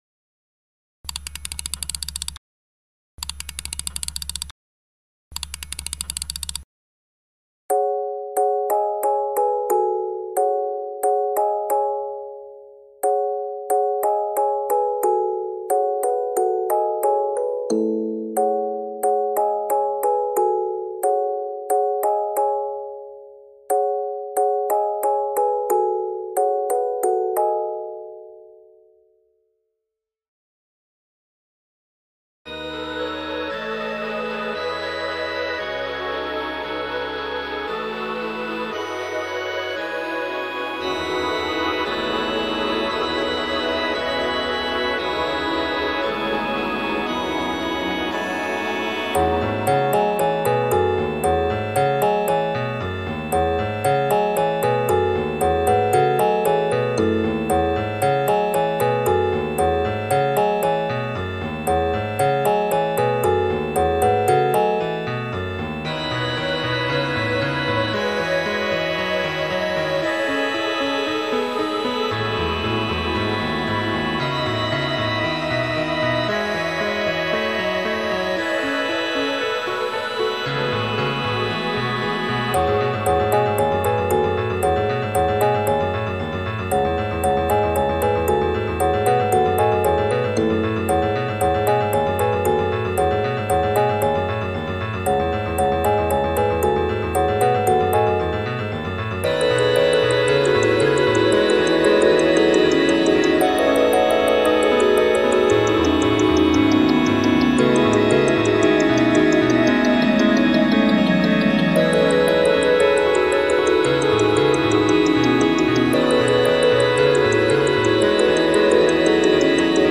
ジャンル：キャラテーマ
最後の爆発音、元の音より小さくしていますが、聴いてて自分も吃驚します...(;;´Д`)